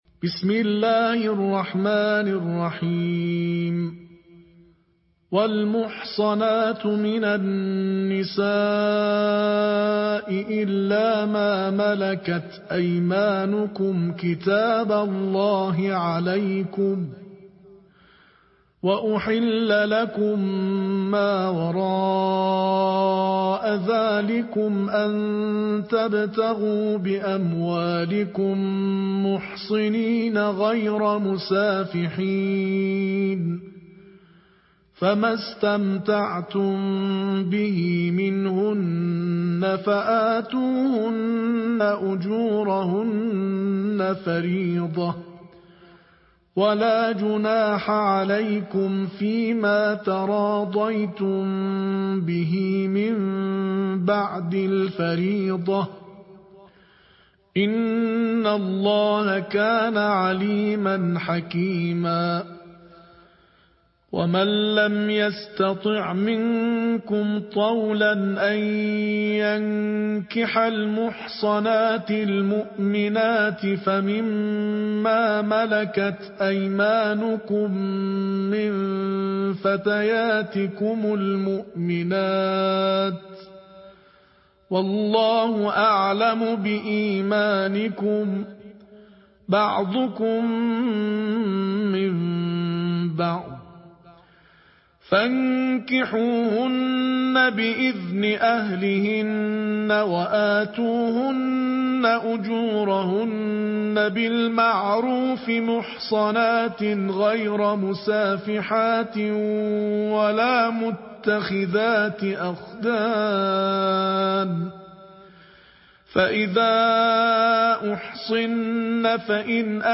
Ежедневное чтение Корана: Тартиль 5-го джуза